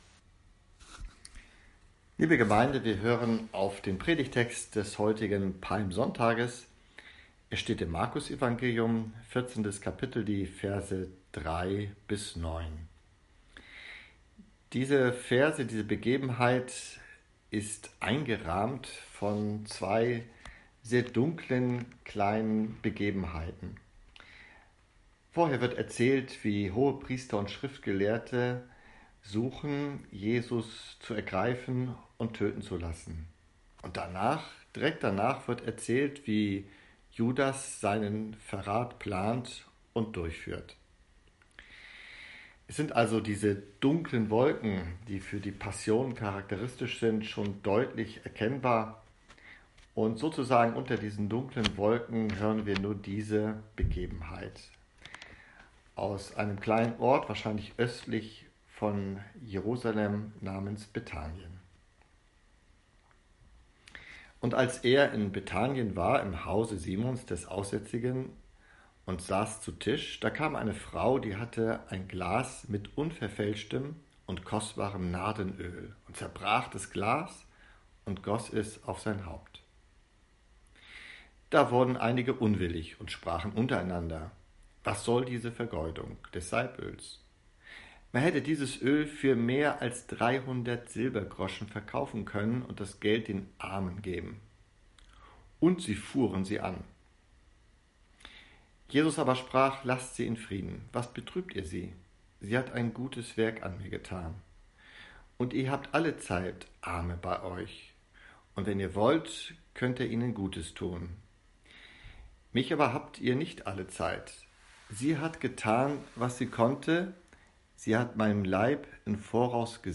Auf dieser Seite können einige Predigten angehört und die letzten hier veröffentlichten Gemeindebriefe heruntergeladen werden.